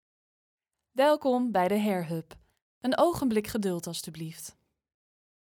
Young, Natural, Playful, Accessible, Friendly
Telephony